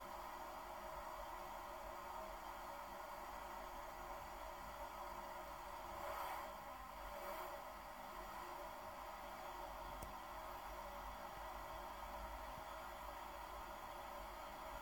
J'ai à nouveau enregistré le bruit qui me semble avoir évolué depuis quelques mois (où j'avais enregistré la première fois).
Le nouvel enregistrement fait penser à ce qu'on entend quand on fait bouillir de l'eau dans une casserole.
Non il n'y a pas de claquement du tout.
La résistance chauffe fort, elle est en contact avec l'eau comme tout thermoplongeur, il doit y avoir des bulles autour.